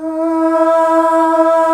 AAAAH   E.wav